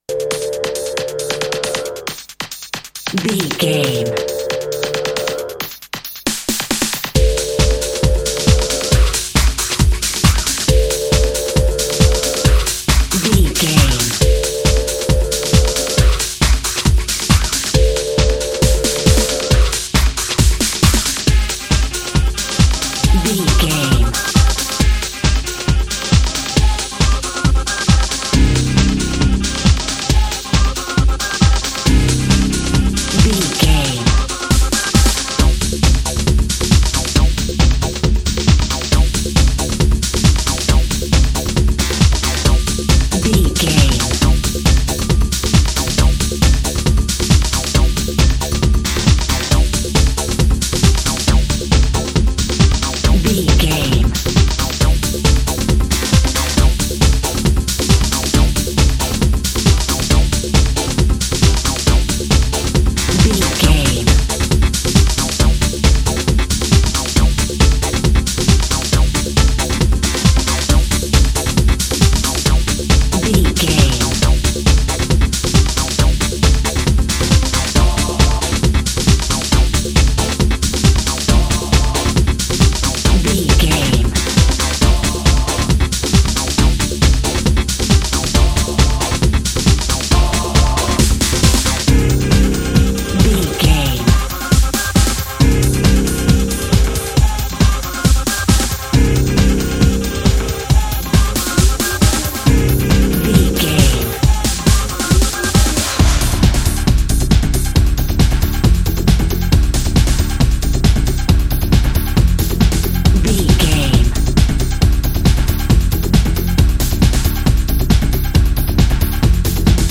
Ionian/Major
Fast
synthesiser
drum machine
Eurodance